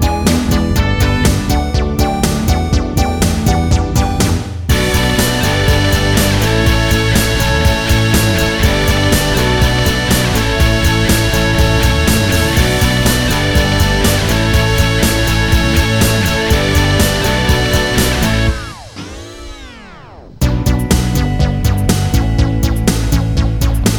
Without Rap Pop (2000s) 3:49 Buy £1.50